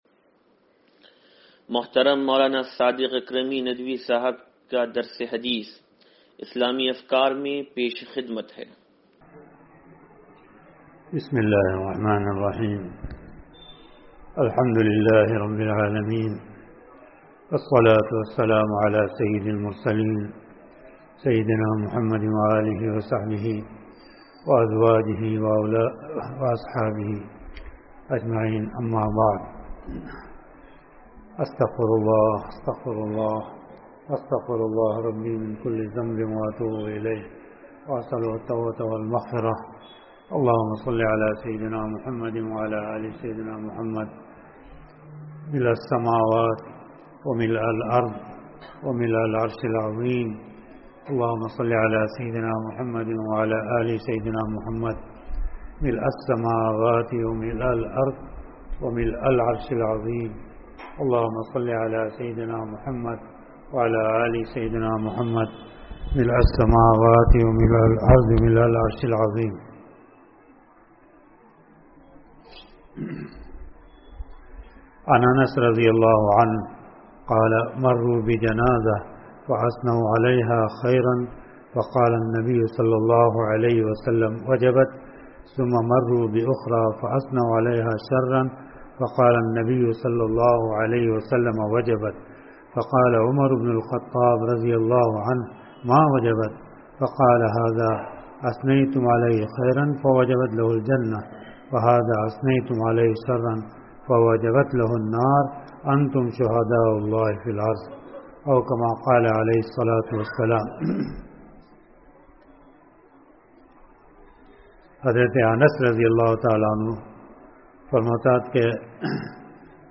درس حدیث نمبر 0746